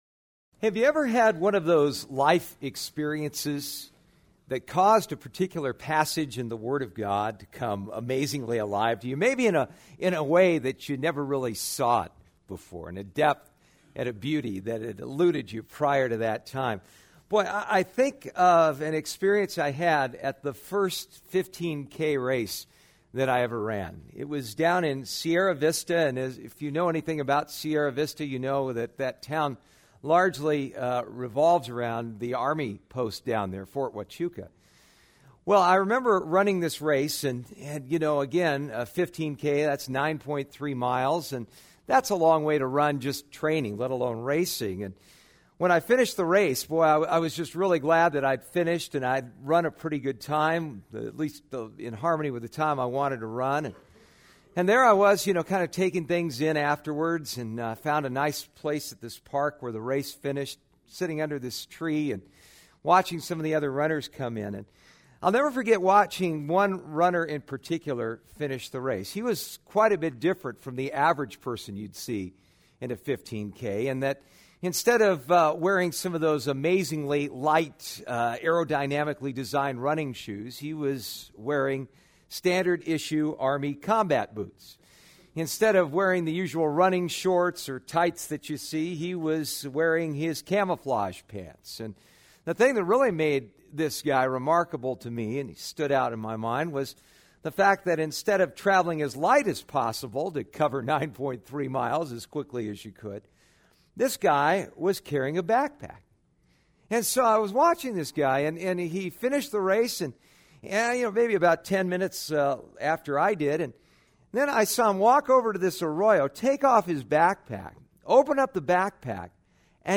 Psalm 36:1-11 Service Type: Sunday Morning « The Power of the Perspective Genesis 30